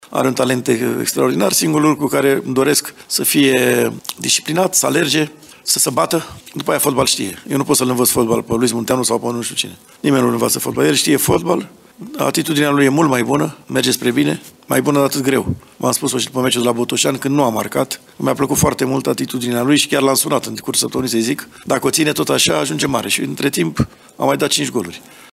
Antrenorul său, Dan Petrescu, îi apreciază atitudinea arătată în perioada recentă:
2-Petrescu-despre-Louis-Munteanu.mp3